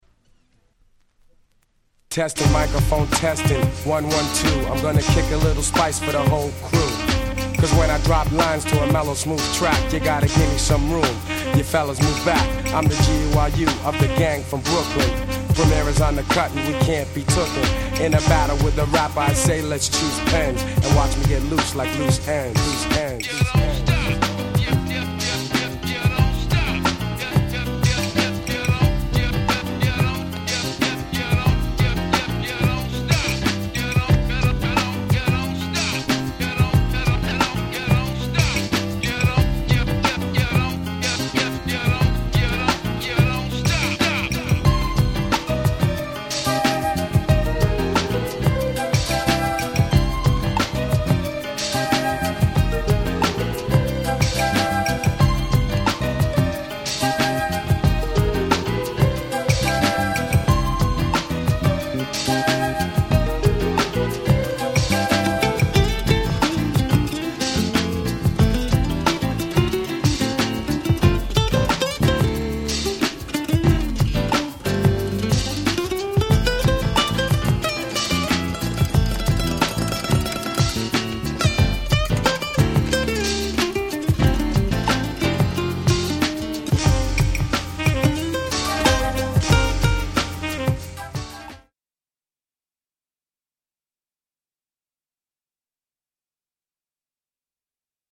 UK Original Press.
UK Soul Classics !!